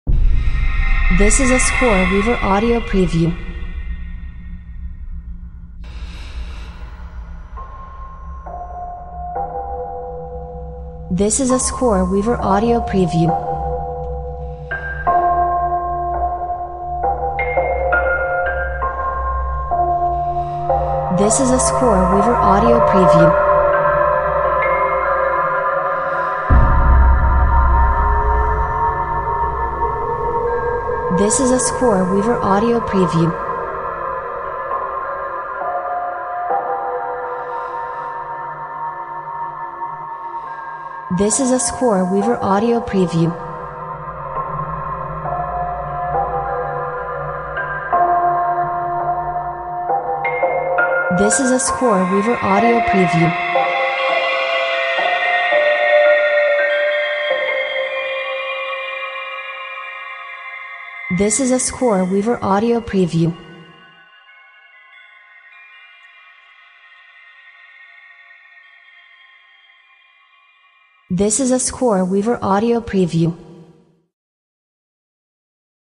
Scary background music featuring an old detuned Piano.